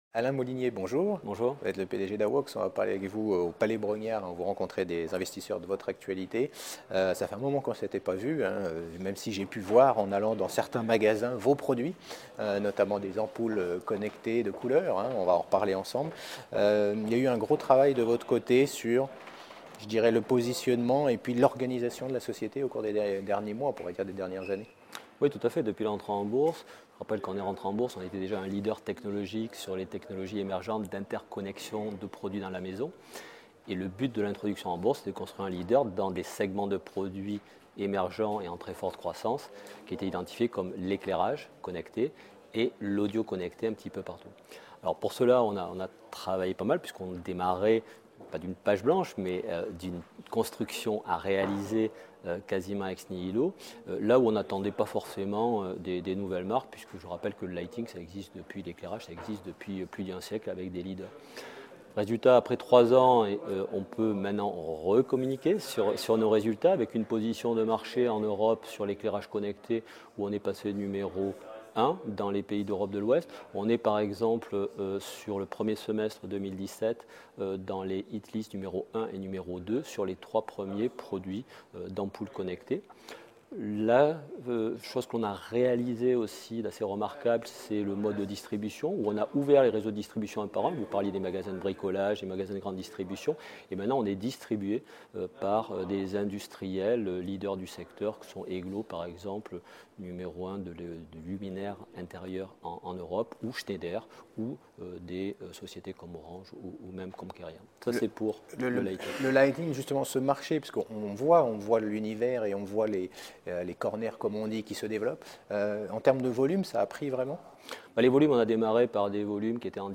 Lors de l’European Large & Midcap Event 2017 organisé par CF&B Communication à Paris, la Web TV partenaire a rencontré de nombreux dirigeants